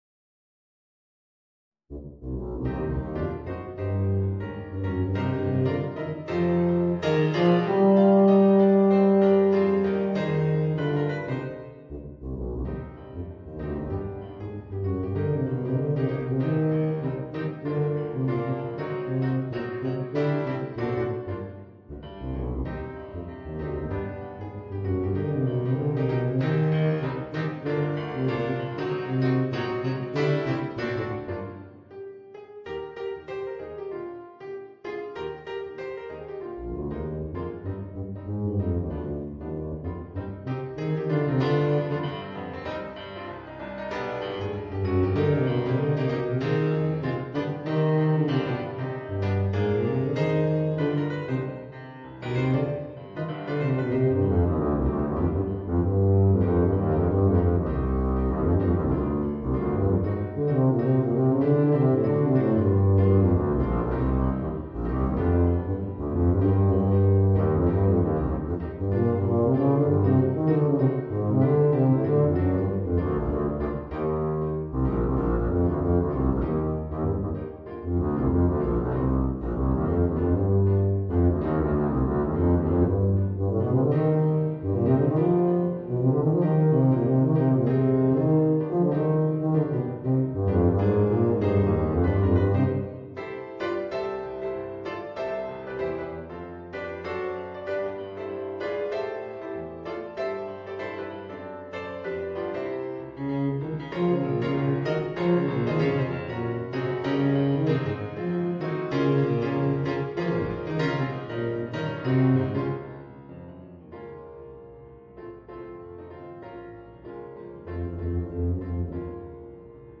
Voicing: Bb Bass and Piano